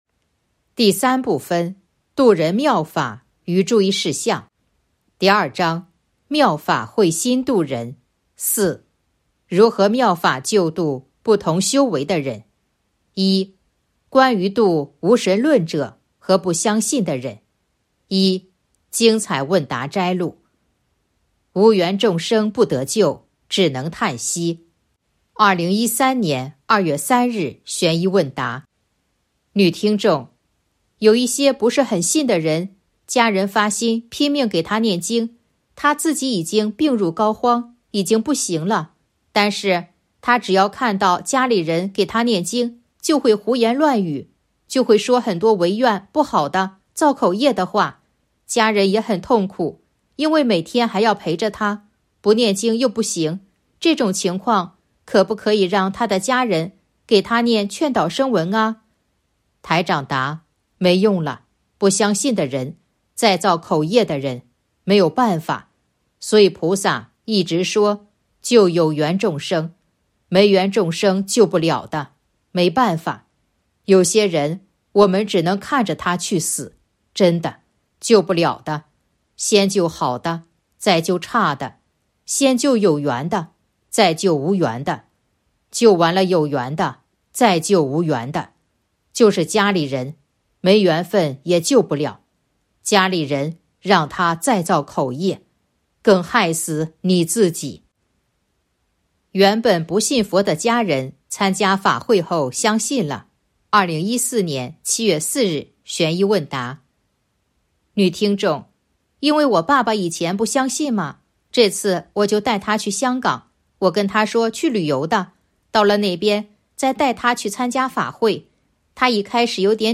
精彩问答摘录《弘法度人手册》【有声书】